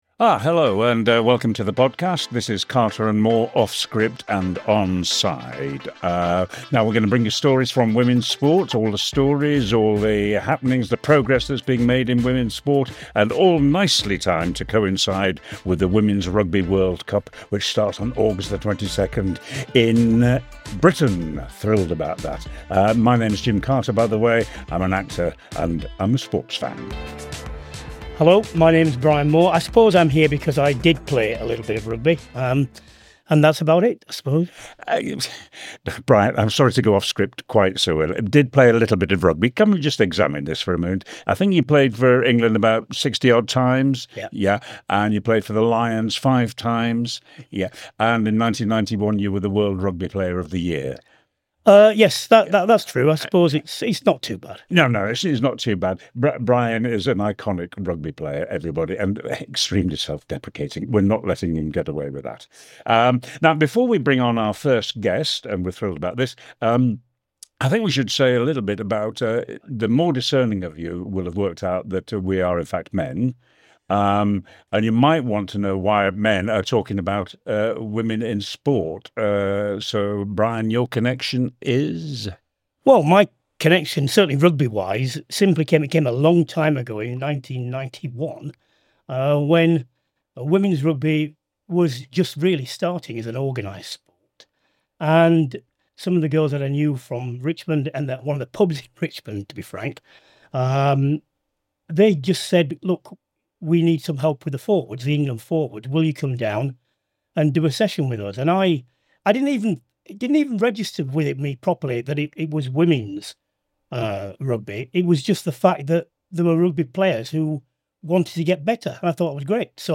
Welcome to our new Women's Rugby World Cup series, Off-Script and onside, hosted by Actor and sports fan Jim Carter, alongside ex-England rugby player Brian Moore.